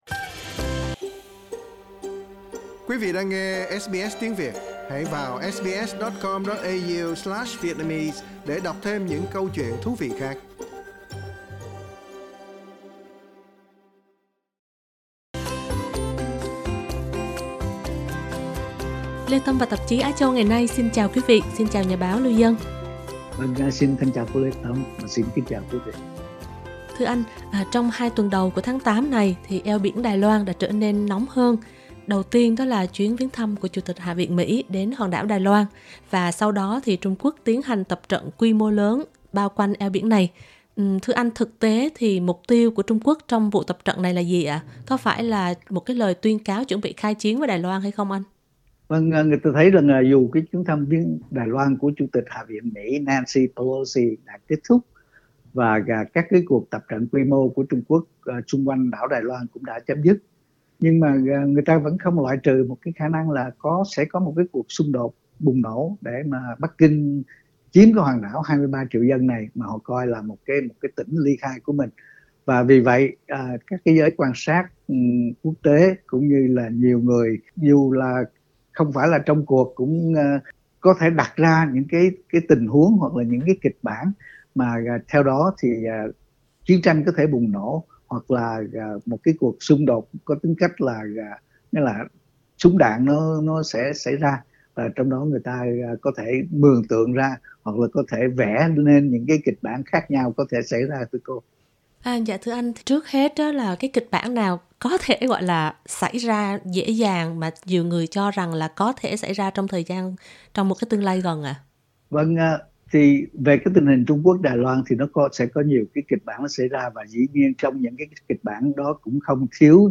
bình luận